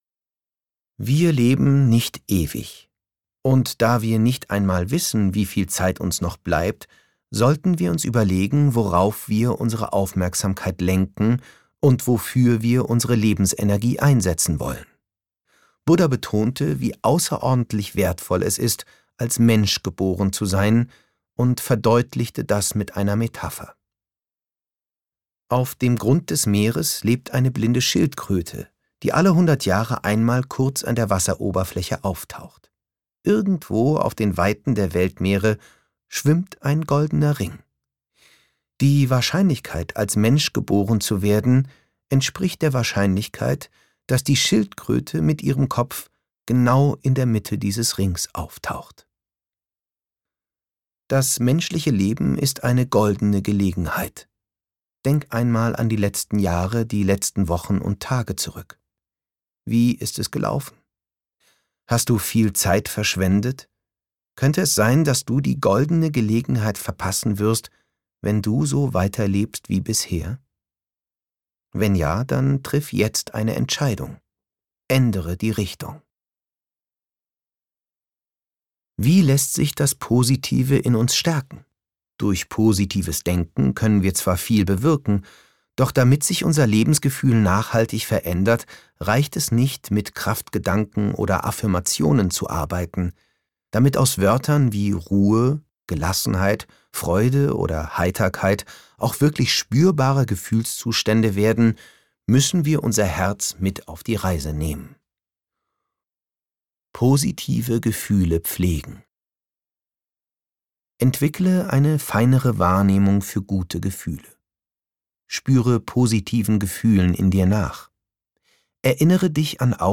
Märchen und Meditationen über den Sinn des Lebens